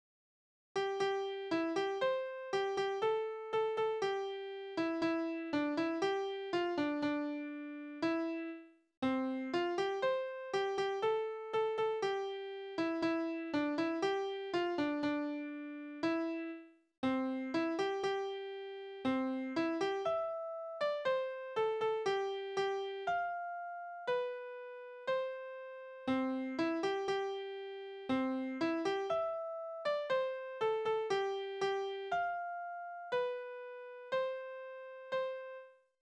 Tonart: C-Dur
Taktart: 2/4
Tonumfang: Undezime
Besetzung: vokal